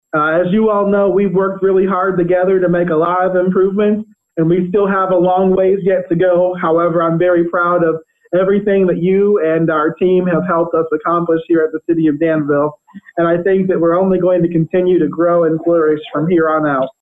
Williams told alderman during Tuesday night’s city council meeting that he saw people of all different groups participating in the march and rally.